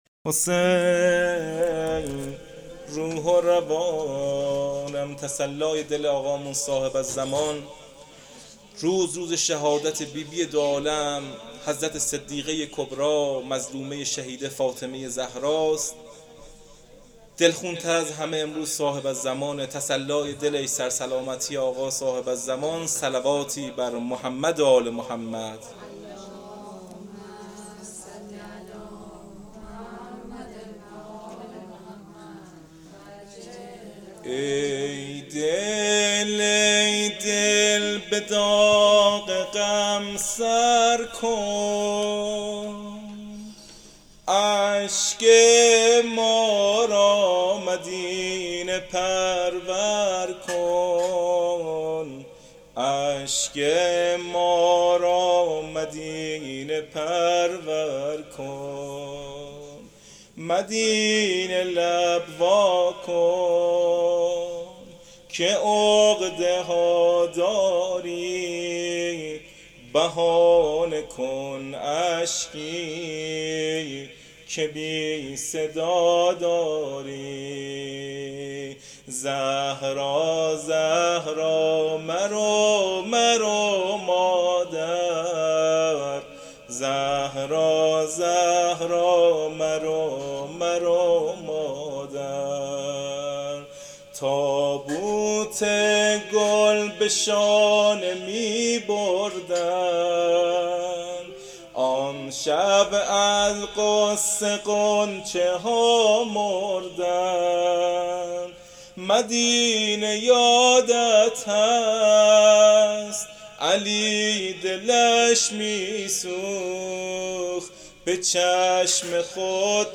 روضه شهادت حضرت زهرا
مداح اهل بیت